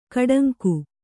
♪ kaḍaŋku